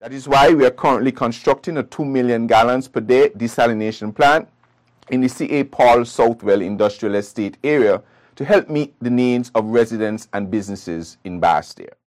In a statement, Federal Minister of Public Infrastructure and Utilities, the Hon. Konris Maynard commented on the significance of the theme to the federation: